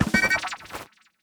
Louie's dispand sound in Pikmin 4.